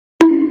Bonk Doge Meme Effect sound effects free download